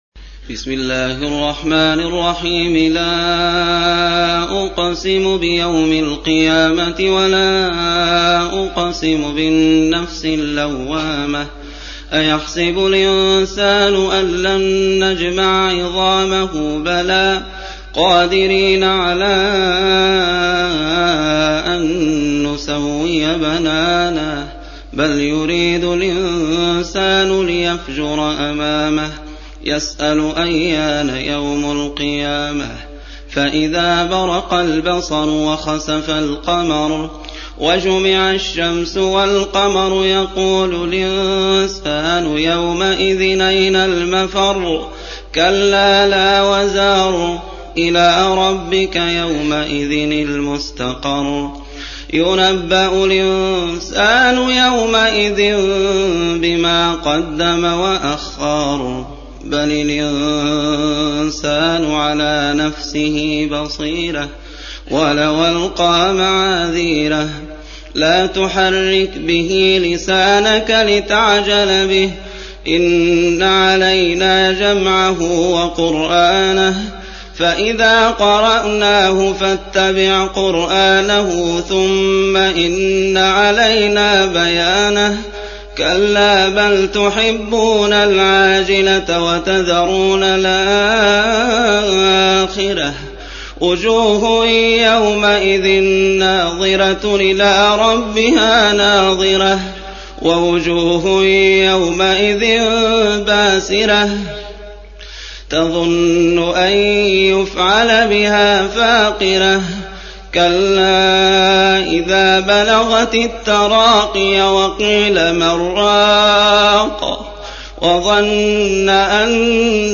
75. Surah Al-Qiy�mah سورة القيامة Audio Quran Tarteel Recitation
Surah Sequence تتابع السورة Download Surah حمّل السورة Reciting Murattalah Audio for 75.